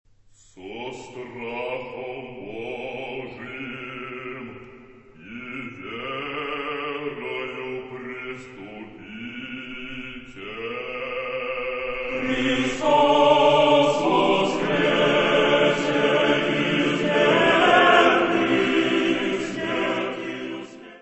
Descrição Física:  1 Disco (CD) (55 min.) : stereo; 12 cm
Área:  Música Clássica